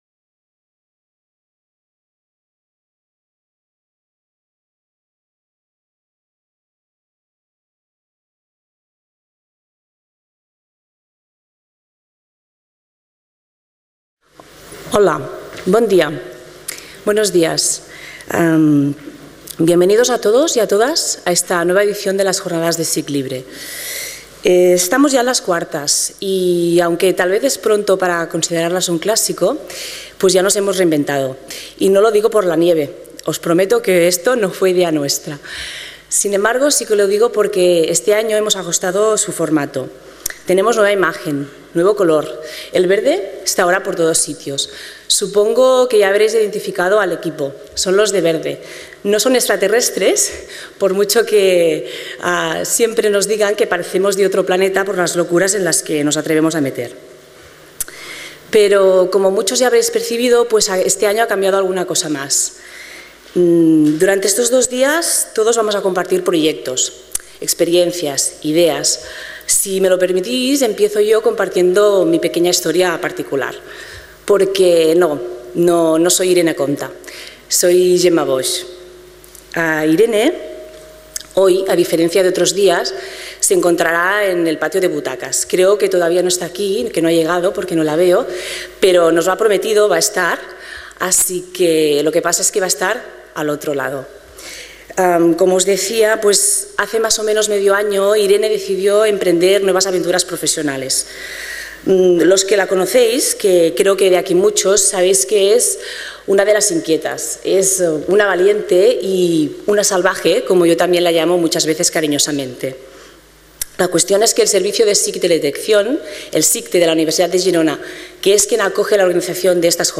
Apertura de las IV Jornadas de SIG Libre